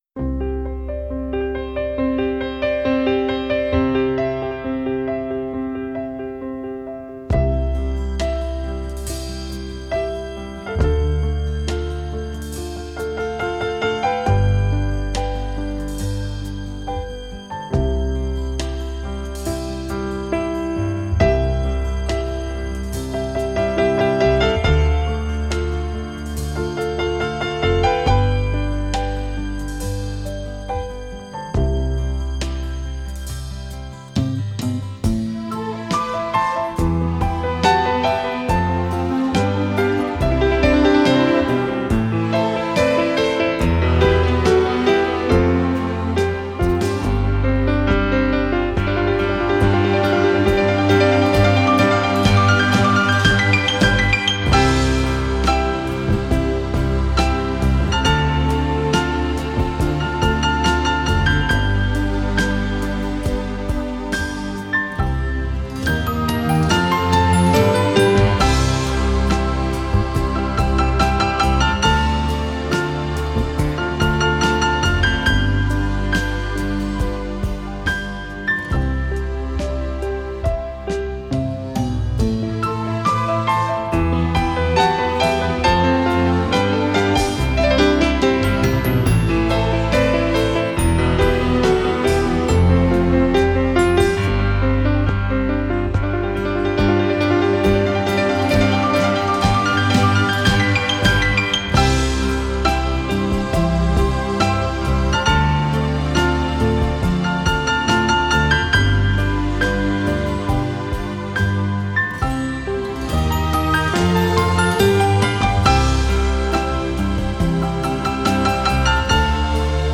موسیقی بیکلام